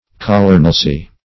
colonelcy - definition of colonelcy - synonyms, pronunciation, spelling from Free Dictionary Search Result for " colonelcy" : The Collaborative International Dictionary of English v.0.48: Colonelcy \Colo"nel*cy\, n. (Mil.)